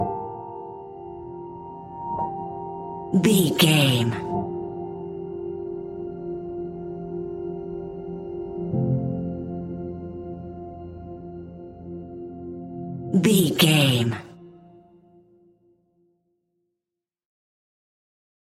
Ionian/Major
F♯
chilled
laid back
Lounge
sparse
new age
chilled electronica
ambient
atmospheric